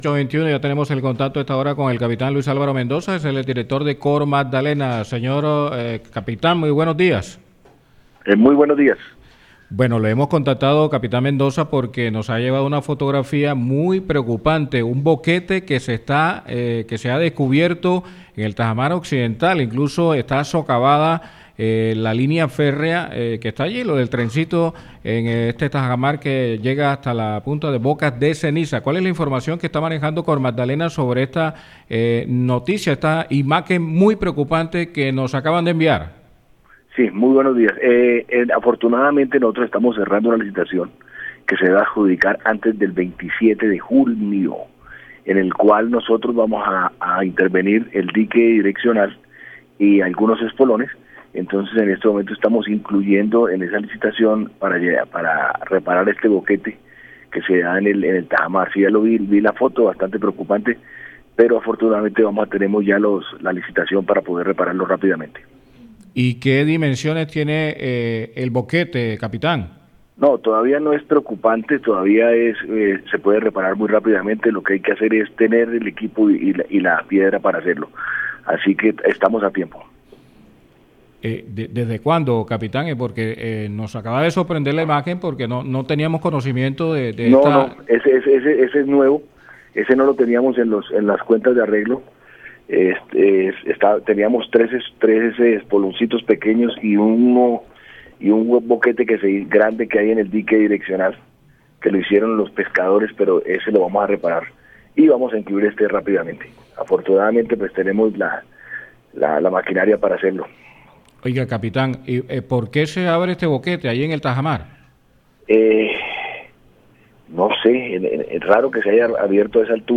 Así lo confirmó el gerente de Cormagdalena, capitán Álvaro Mendoza, en diálogo con Atlántico en Noticias, una vez consultado sobre la preocupante imagen.